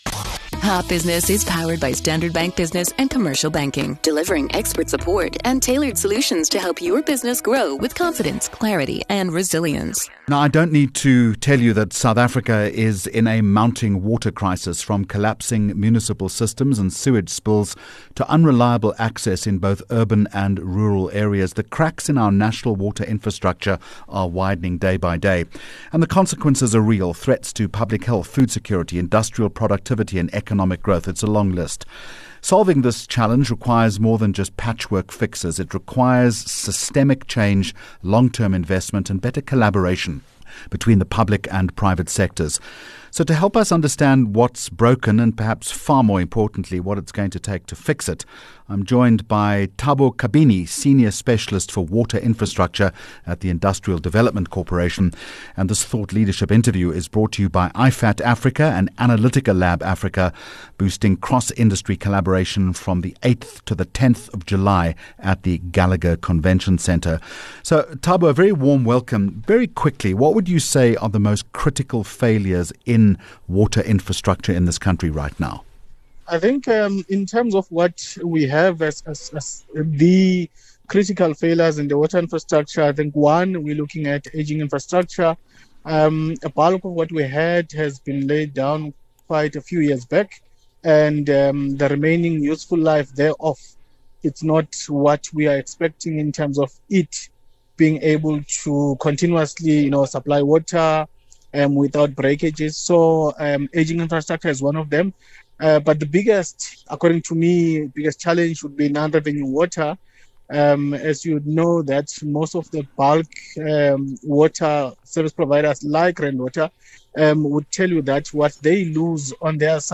Expert Interview Topic: Water, waste and science sectors align to tackle Africa’s infrastructure gaps Guest